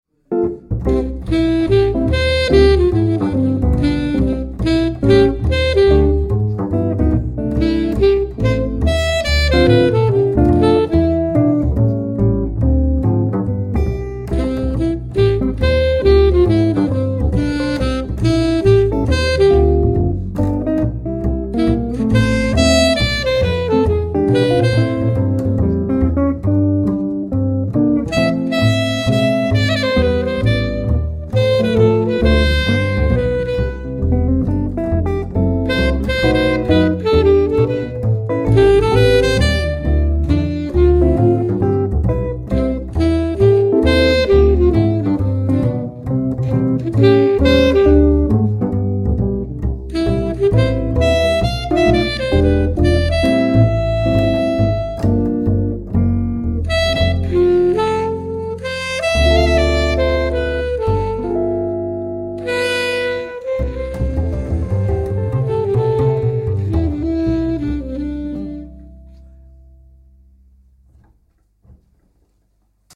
wenn Sie eine Jazzband für Ihre Weihnachtsfeier suchen